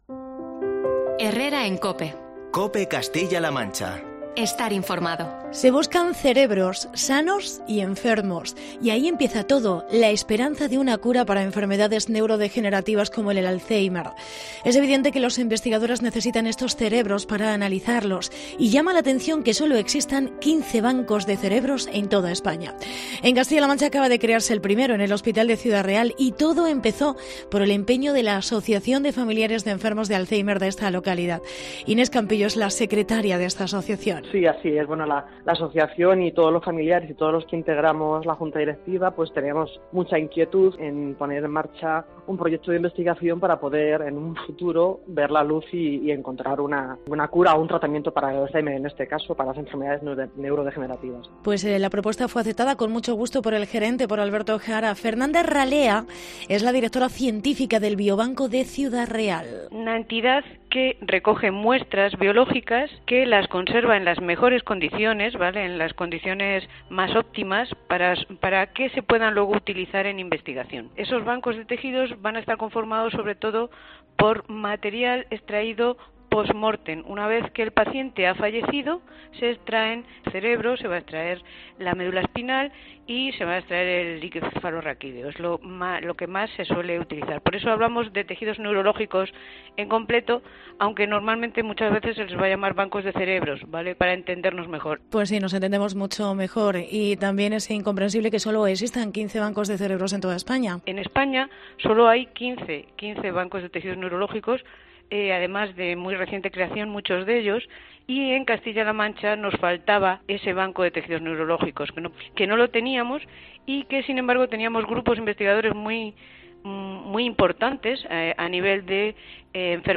Reportaje banco de cerebros en el Hospital de Ciudad Real